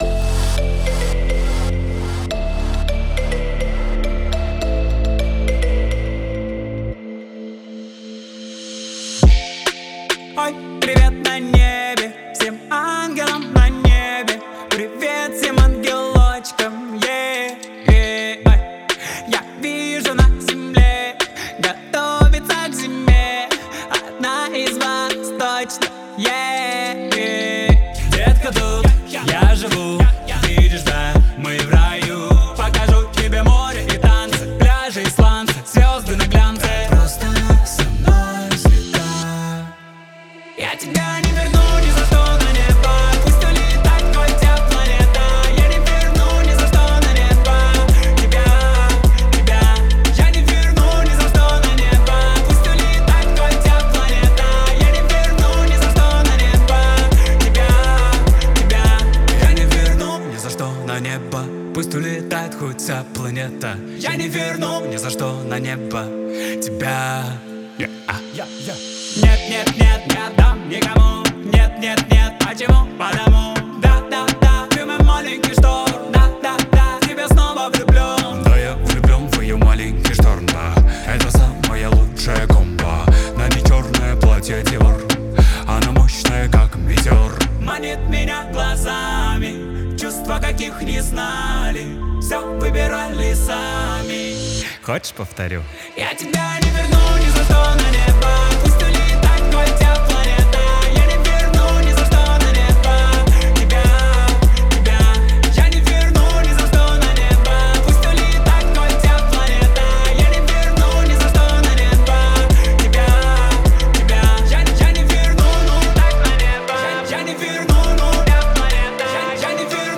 это эмоциональная песня в жанре поп-рок